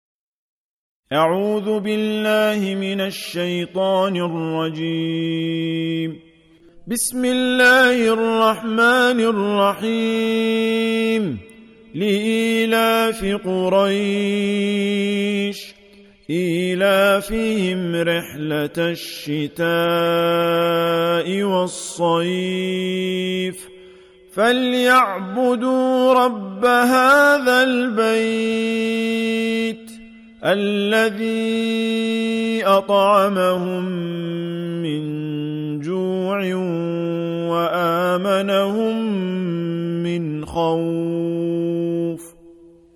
106. Surah Quraish سورة قريش Audio Quran Tarteel Recitation
Surah Repeating تكرار السورة Download Surah حمّل السورة Reciting Murattalah Audio for 106. Surah Quraish سورة قريش N.B *Surah Includes Al-Basmalah Reciters Sequents تتابع التلاوات Reciters Repeats تكرار التلاوات